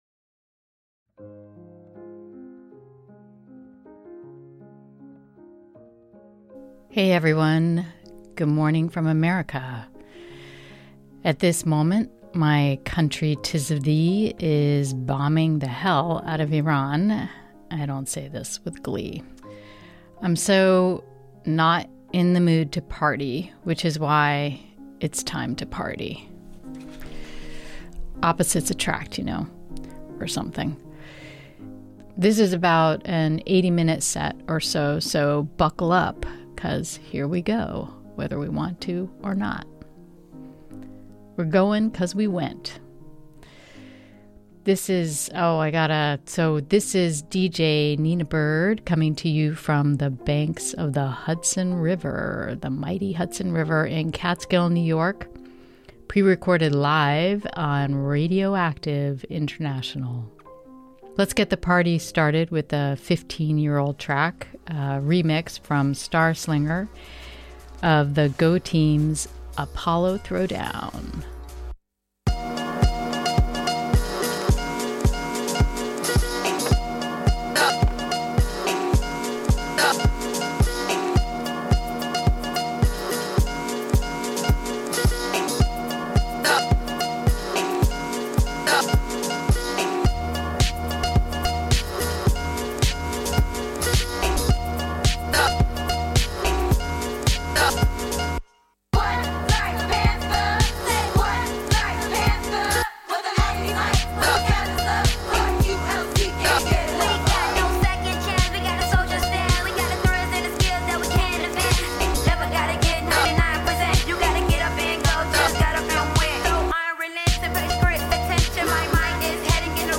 where i tell stories that make not much sense and play music.